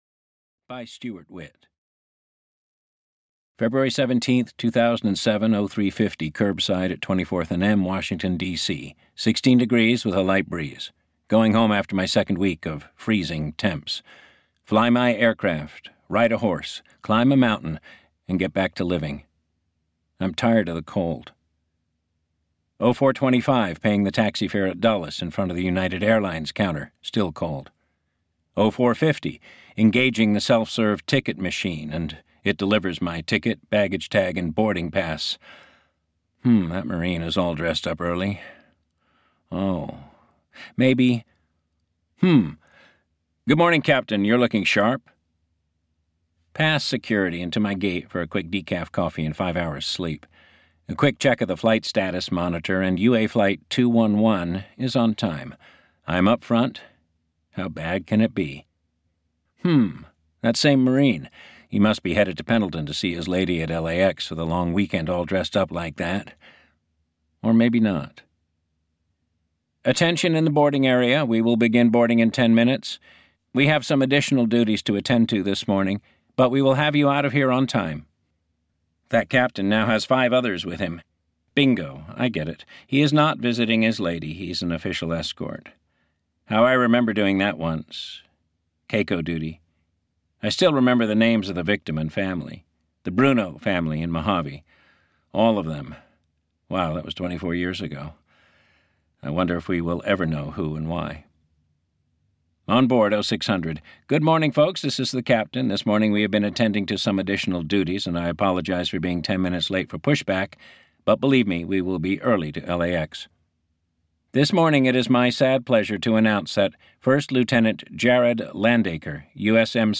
After (EN) audiokniha
Ukázka z knihy